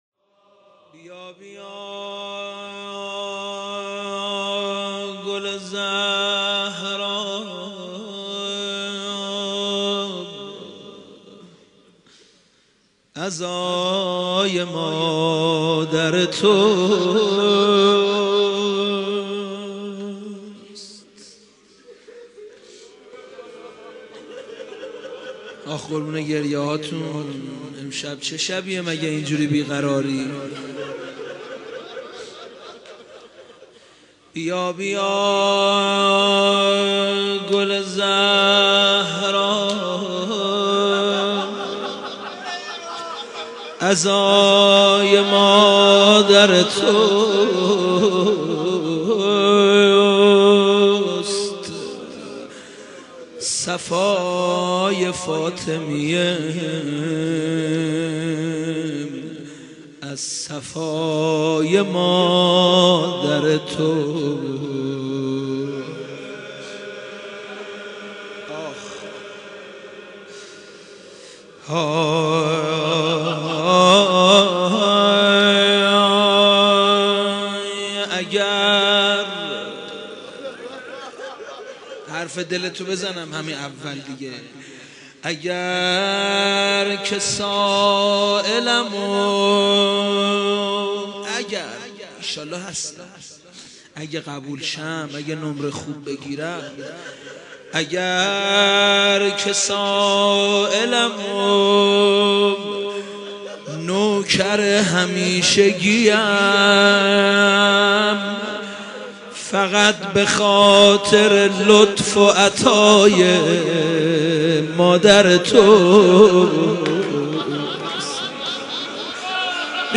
عنوان : مداحی